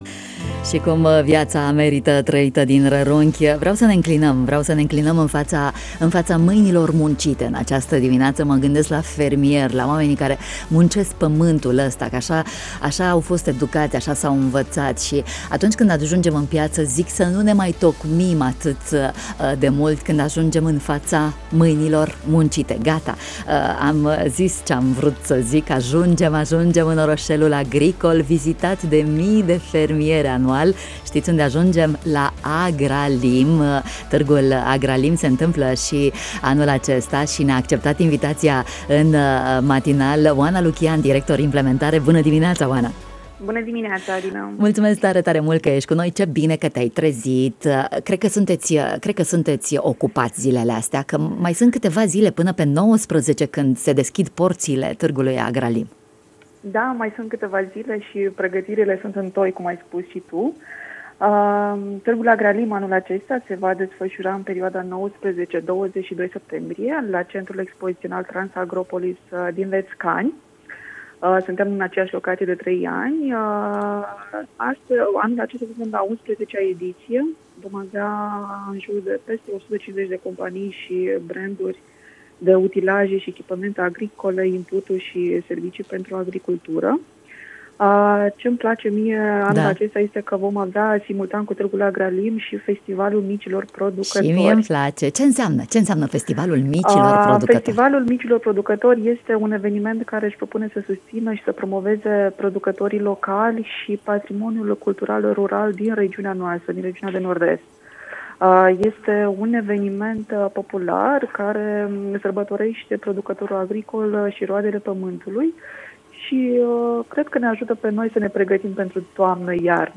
în matinal